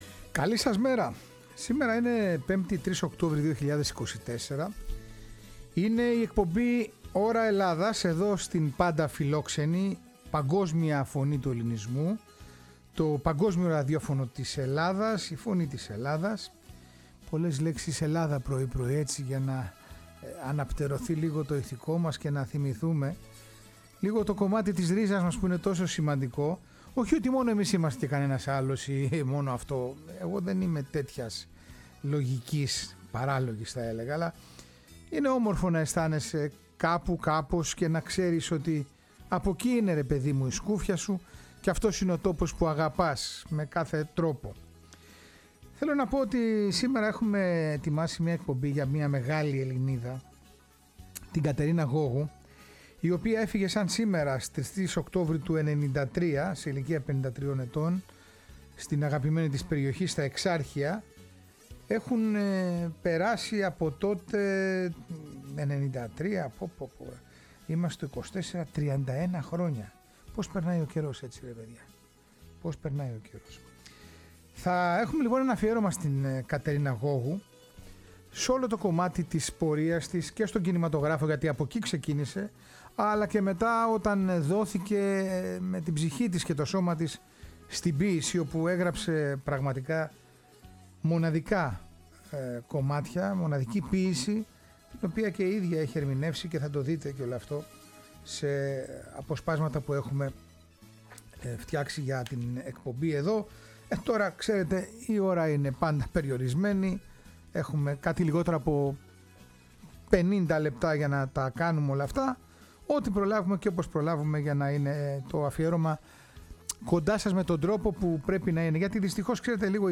Αναζητούμε την πορεία της μέσα στον κοινωνικό ιστό, μιλάμε για τις πολιτικές της θέσεις και διεκδικήσεις. Ακούμε τις φωνές που κατέγραψαν την άποψη και γνώμη τους για την Κατερίνα Γώγου.